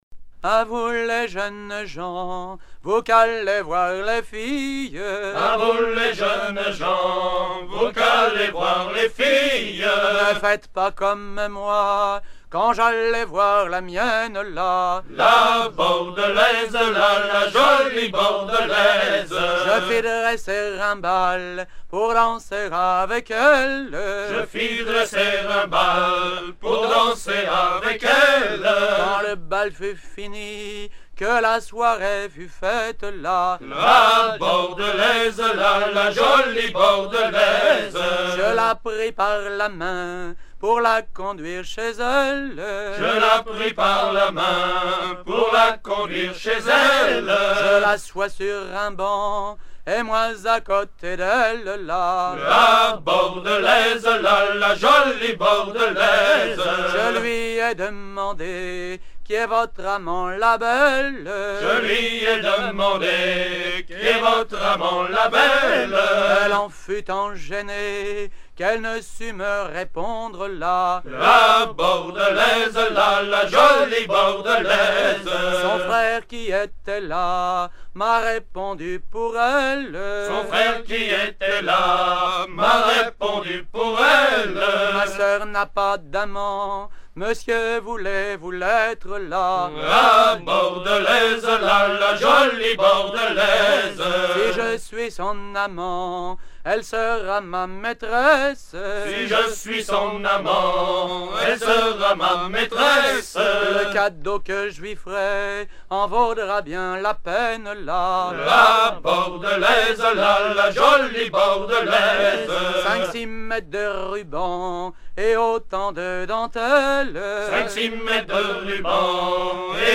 Version recueillie vers 1975 auprès de chanteurs de Fécamp, Saint-Pierre-n-Port, Eletot et Yport
danse : ronde